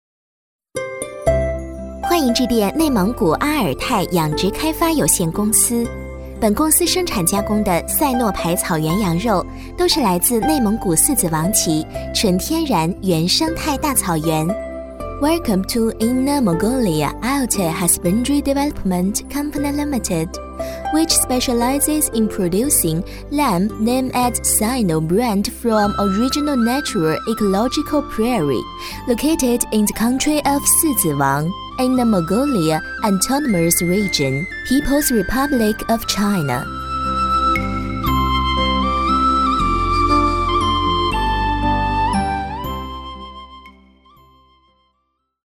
19 女国90_其他_彩铃_内蒙古阿尔泰养殖开发有限公司 女国90
女国90_其他_彩铃_内蒙古阿尔泰养殖开发有限公司.mp3